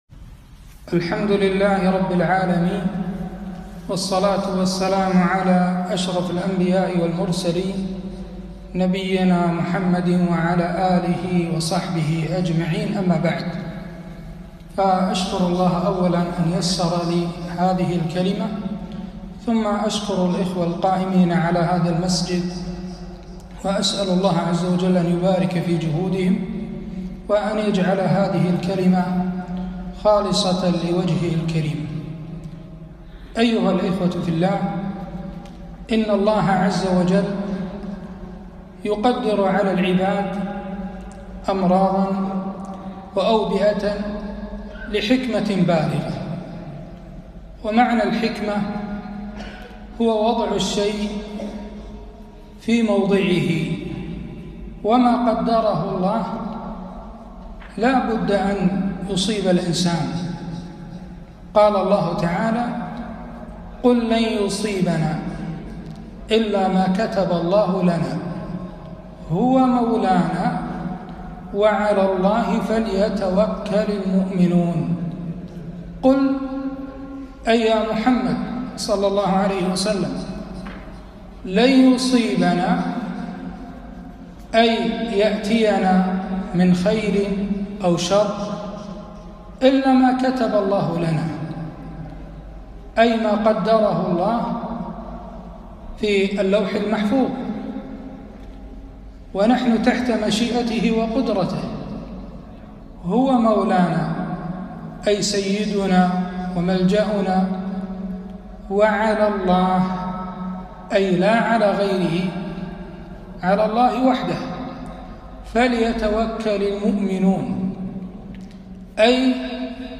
محاضرة - قل لن يصيبنا إلا ماكتب الله لنا